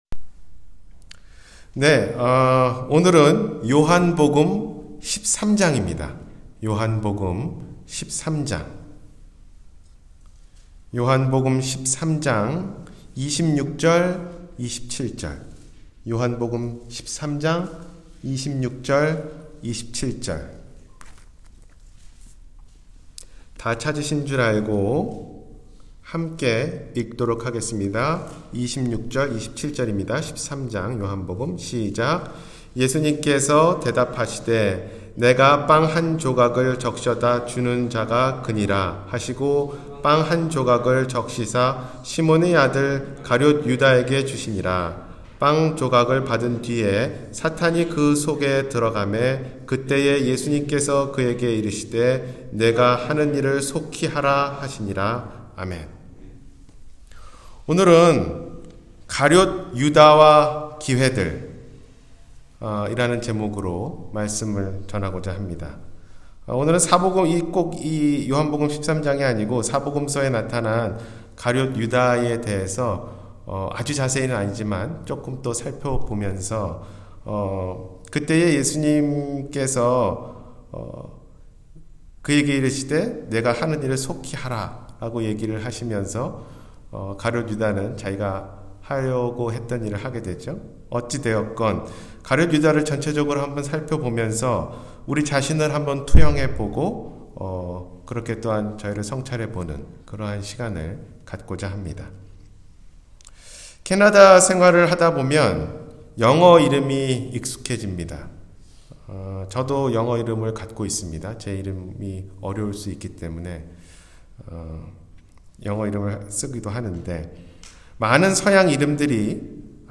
가룟 유다와 기회들 – 주일설교